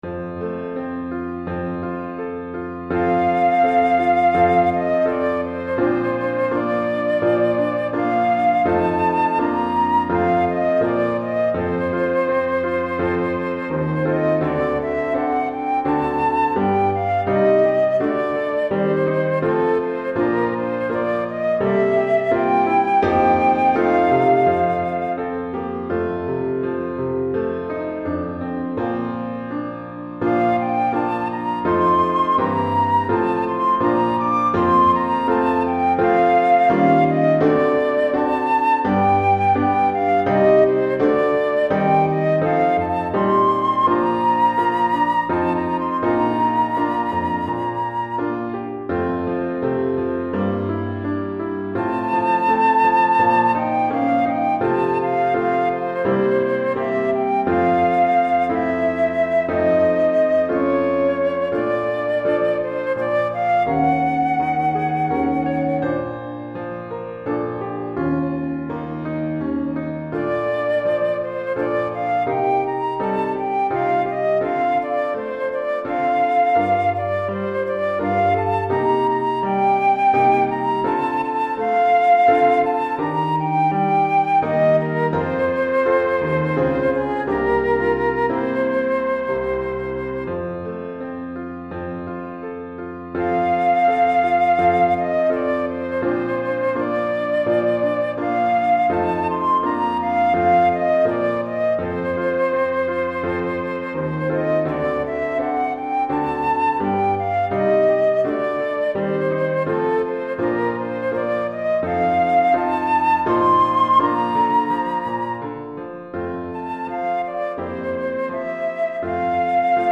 Flûte Traversière et Piano